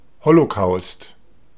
Ääntäminen
IPA: /ˈhoːloˌkaʊ̯st/ IPA: /ˌholoˈkaʊ̯st/